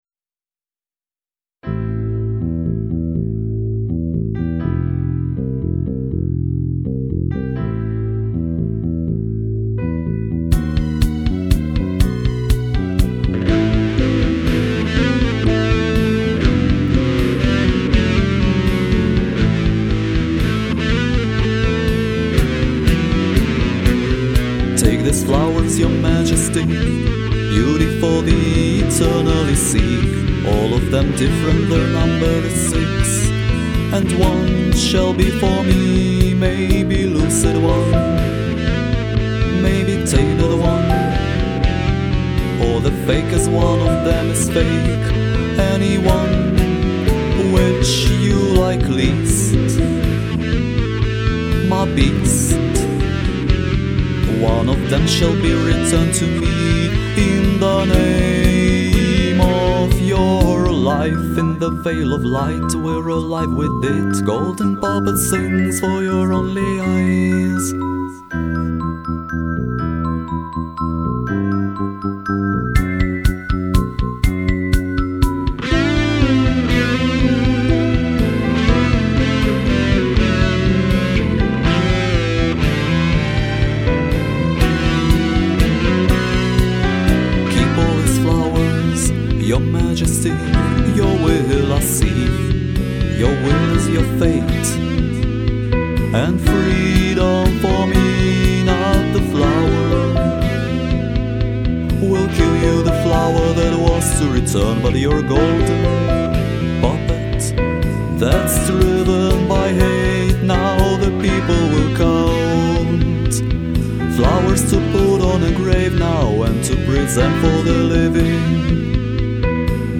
первое студийное демо.